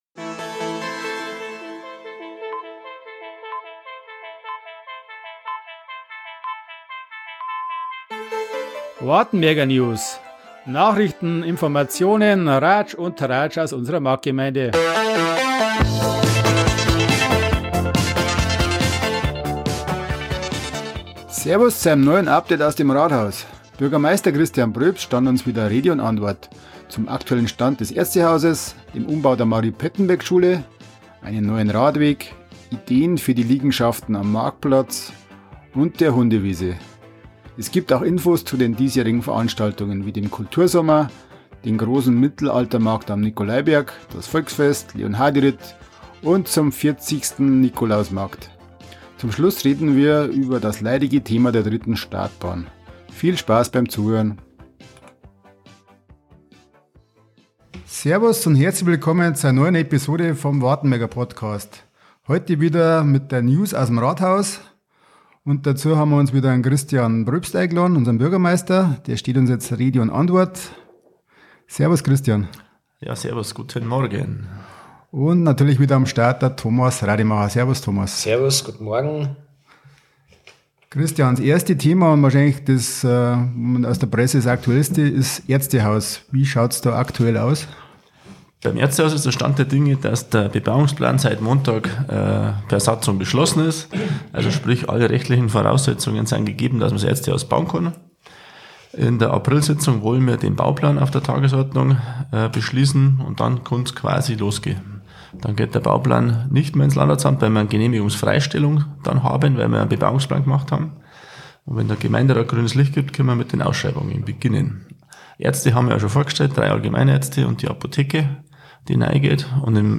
News aus dem Wartenberger Rathaus Bürgermeister Christian Pröbst stand uns zu folgende Themen Rede und Antwort: Ärztehaus Nahwärmenetz Erweiterung Gewerbegebiet Baugebiet Kleinfeld West Mari...